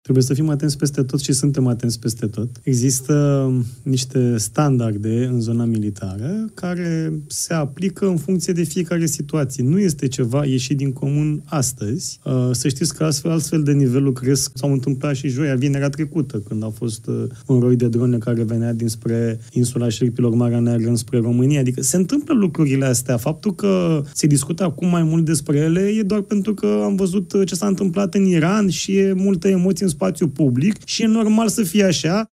„Nu este ceva ieșit din comun”, a declarat ministrul Apărării la Adevărul Live.